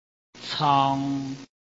臺灣客語拼音學習網-客語聽讀拼-海陸腔-鼻尾韻
拼音查詢：【海陸腔】cong ~請點選不同聲調拼音聽聽看!(例字漢字部分屬參考性質)